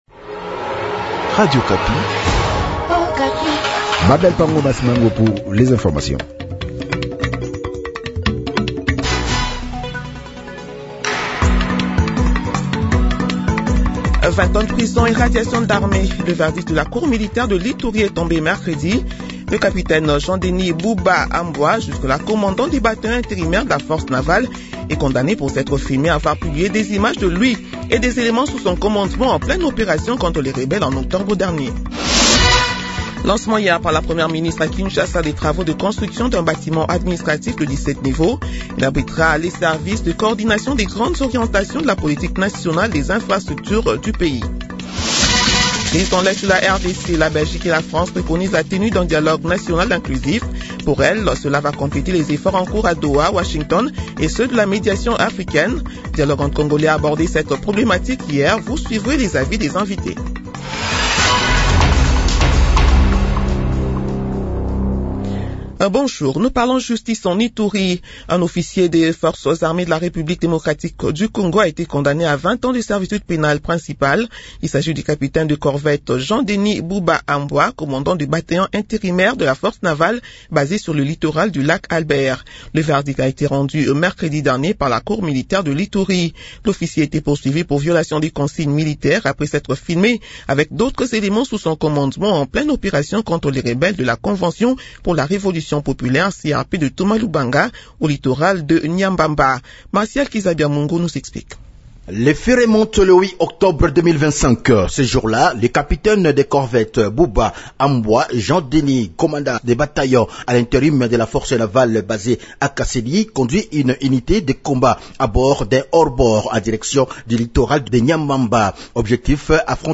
Journal de 8h de vendredi 23 janvier 2026